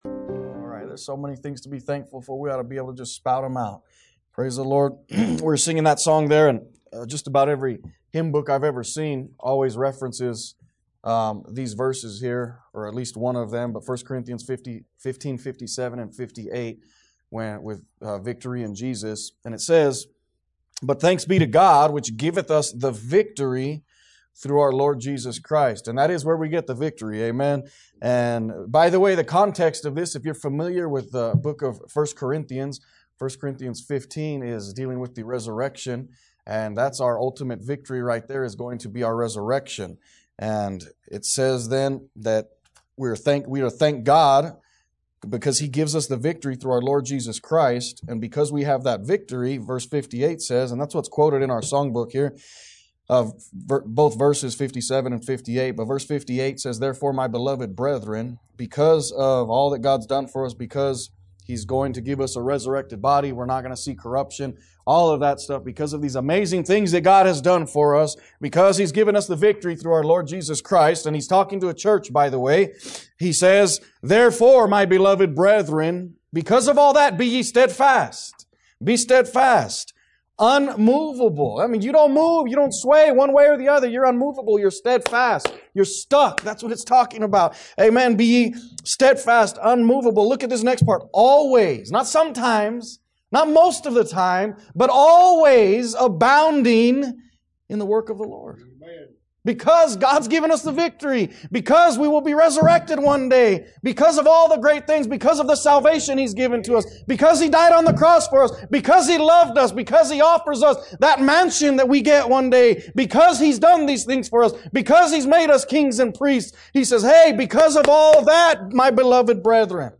A message from the series "The Sermon On The Mount."